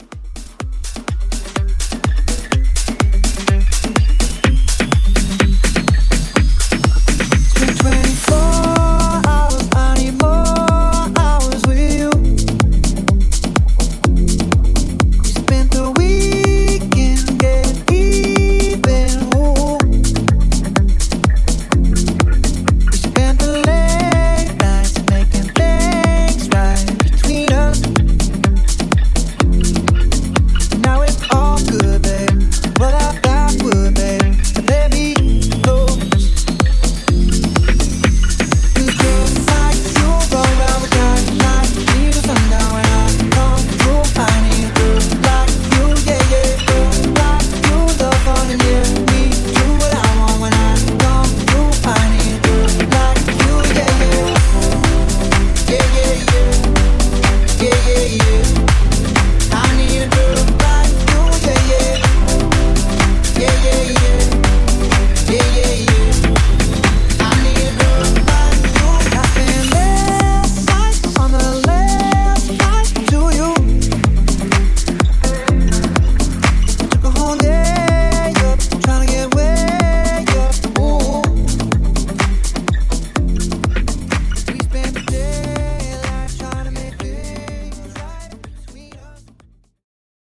Genres: 2000's , R & B , REGGAETON
Clean BPM: 103 Time